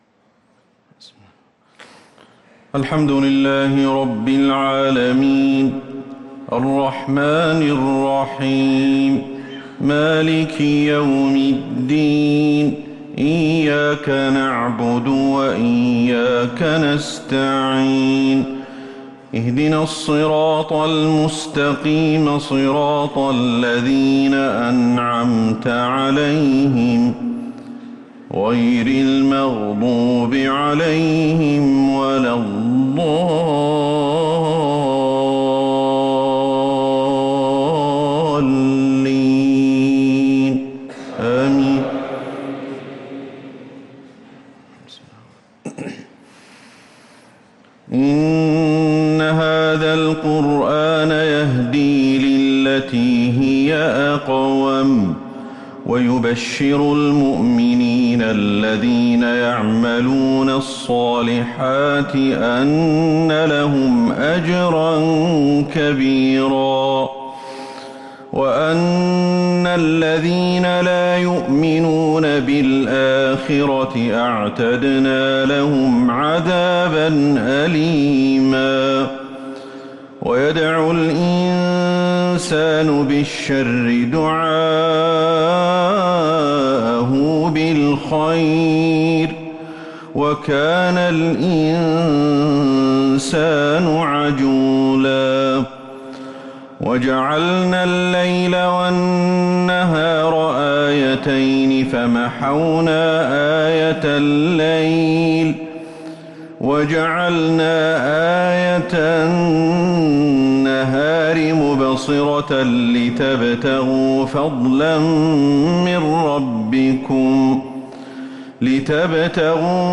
صلاة العشاء للقارئ أحمد الحذيفي 6 محرم 1445 هـ